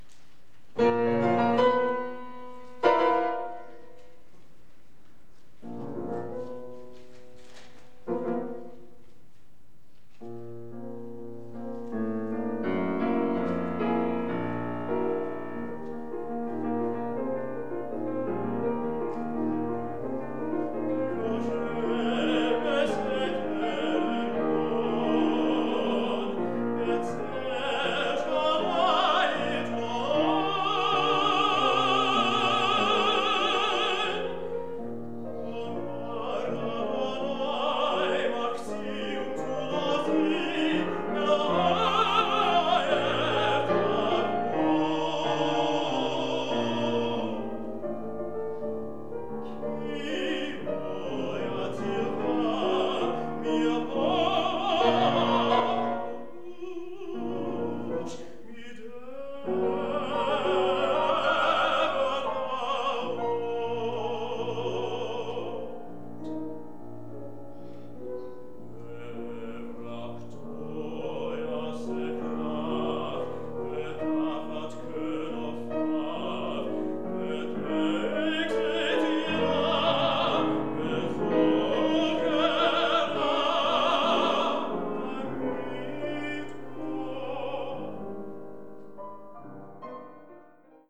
Jüdische Musik
Tenor
Klavier